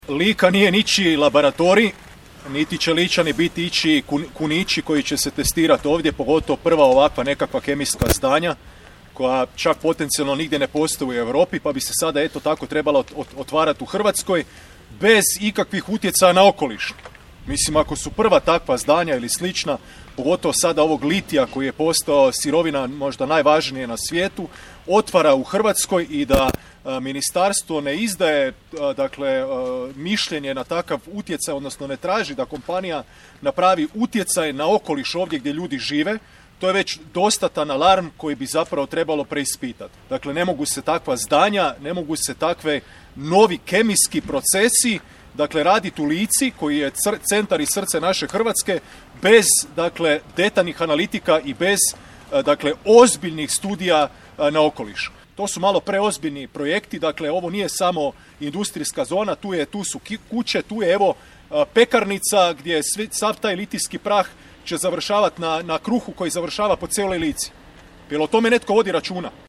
Ispred novoizgrađene tvornice za obradu litija u poslovnoj zoni Smiljansko polje u Gospiću danas je održana press konferencija Mosta, na kojoj je upozoreno na nedostatak transparentnosti projekta i moguće posljedice po okoliš i zdravlje građana.